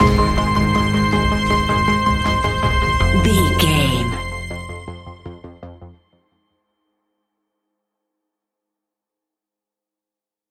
Aeolian/Minor
ominous
dark
eerie
synthesiser
drums
instrumentals
horror music